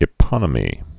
(ĭ-pŏnə-mē)